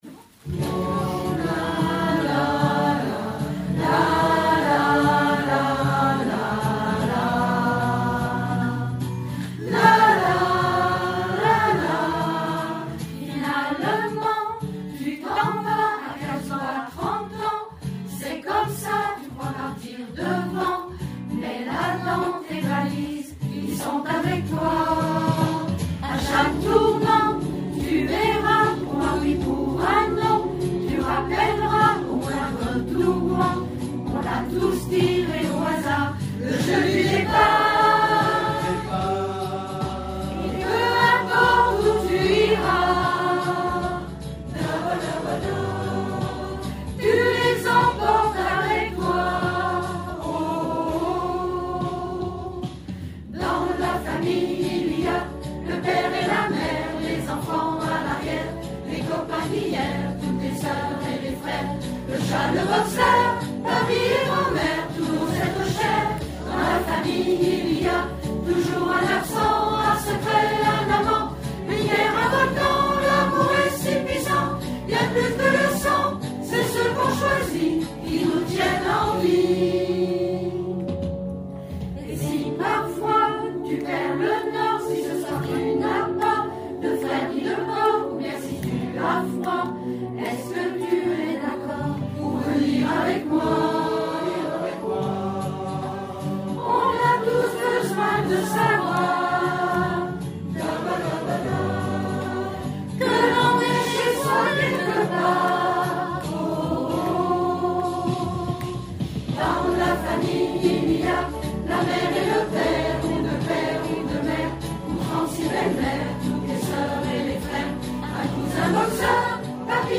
concert de mars 2024